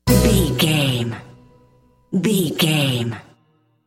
Aeolian/Minor
orchestra
percussion
silly
circus
goofy
comical
cheerful
perky
Light hearted
secretive
quirky